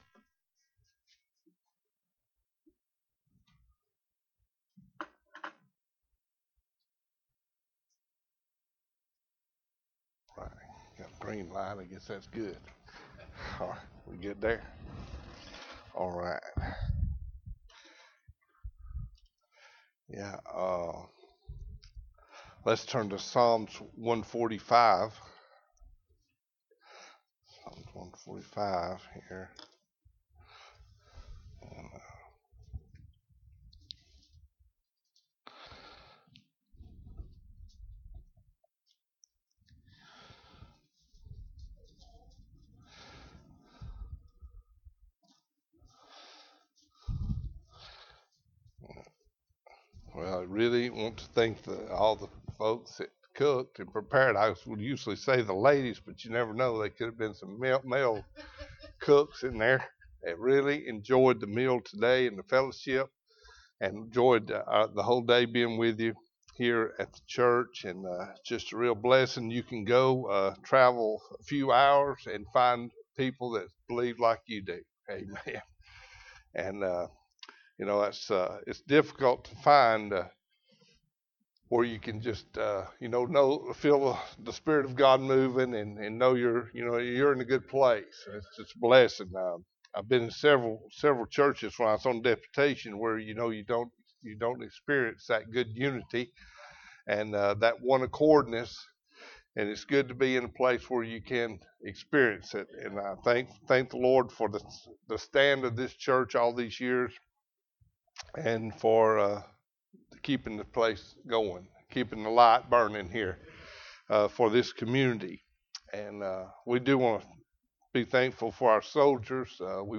Passage: Psalm 145: 1-2 Service Type: Sunday Evening Bible Text